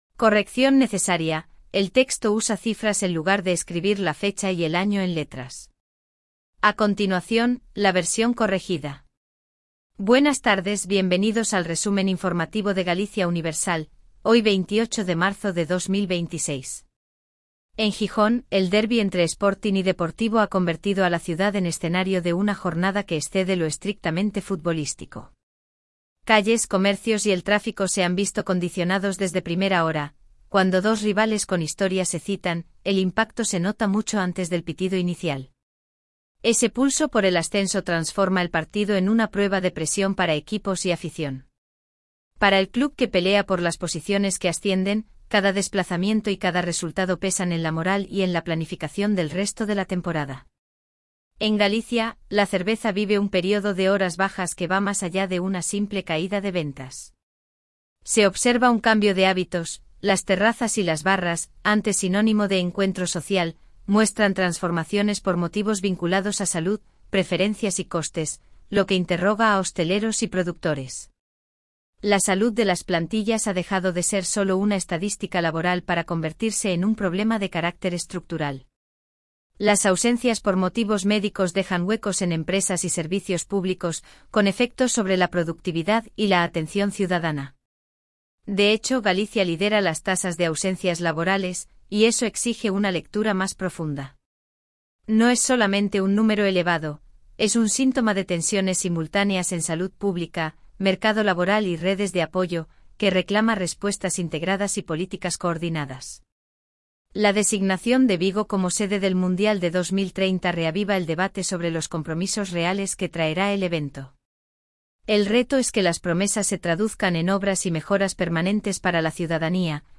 Resumo informativo de Galicia Universal